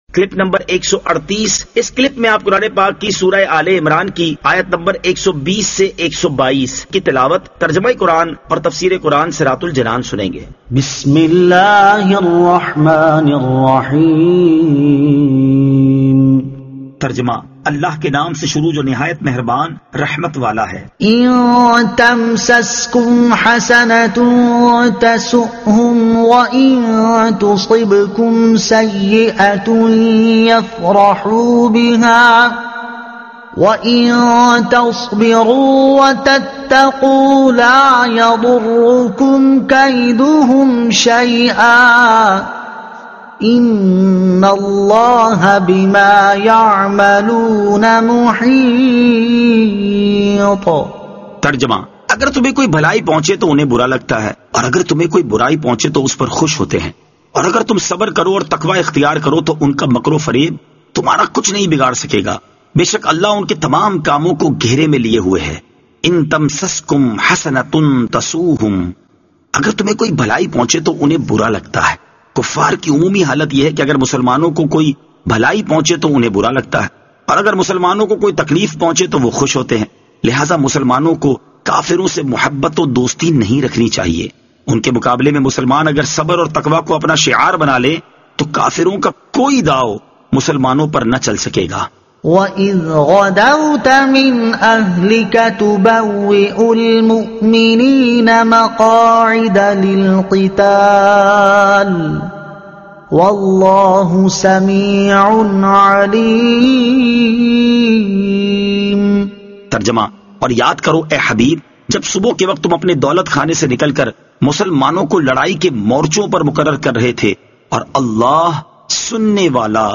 Surah Aal-e-Imran Ayat 120 To 122 Tilawat , Tarjuma , Tafseer